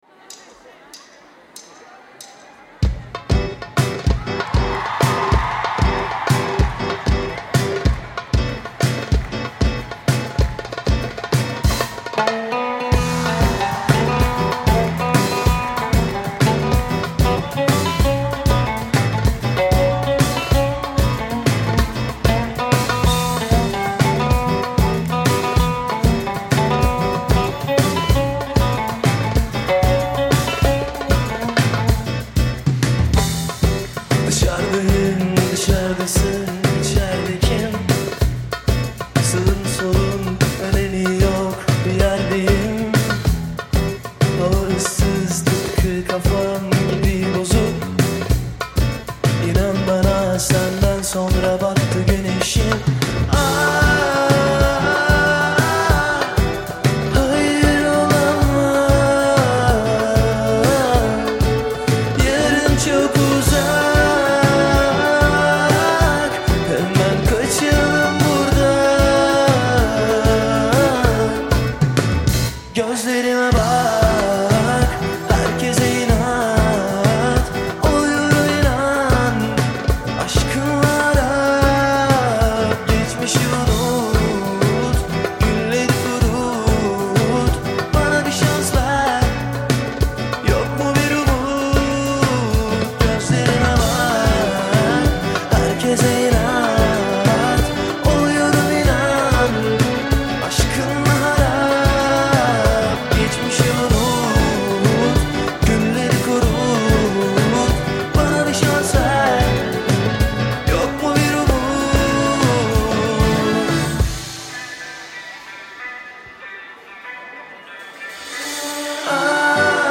(ورژن کنسرت)